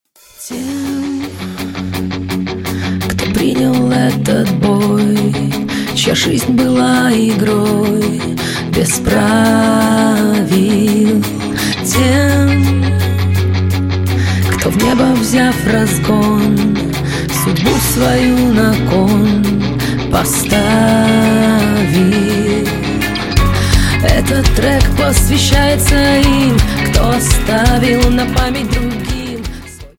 • Качество: 128, Stereo
Alternative Rock
сильные
русский рок